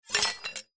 Metal Drop